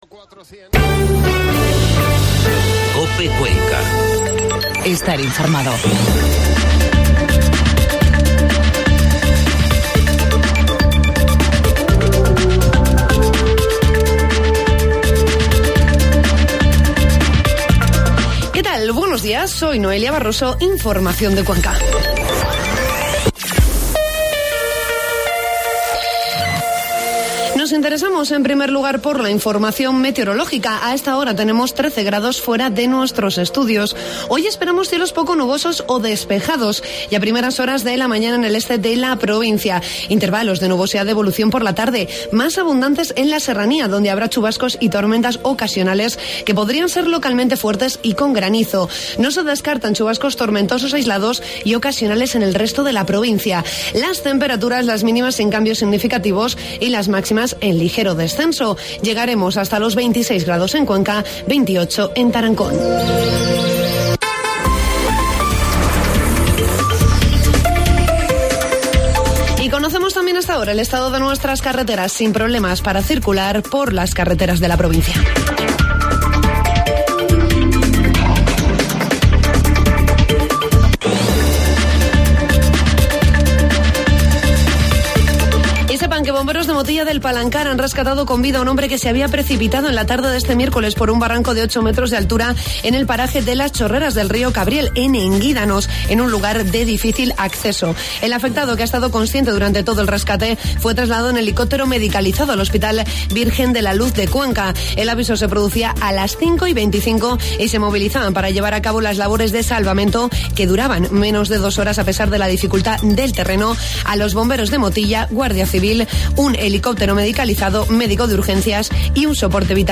AUDIO: Informativo matinal COPE Cuenca.